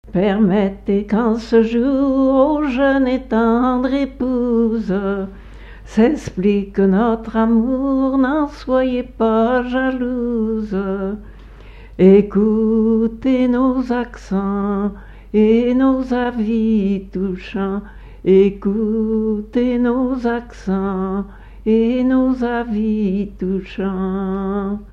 circonstance : fiançaille, noce ;
Genre strophique
Pièce musicale inédite